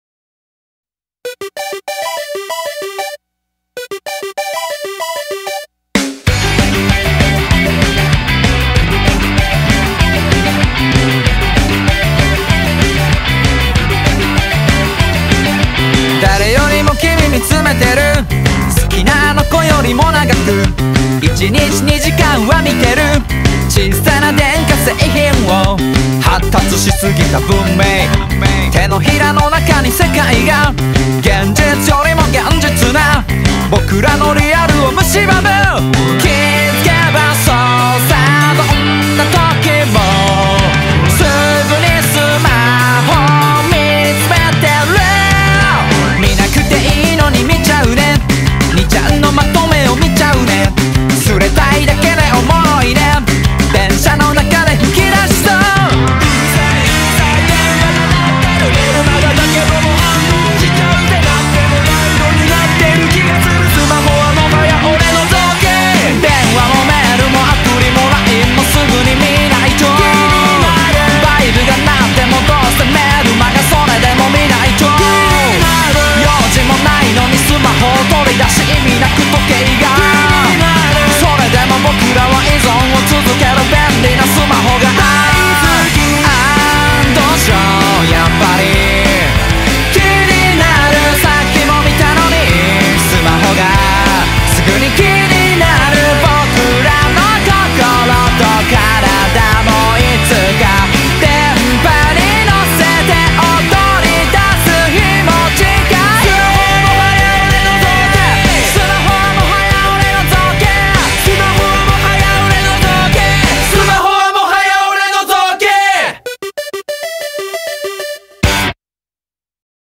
BPM192
Audio QualityPerfect (High Quality)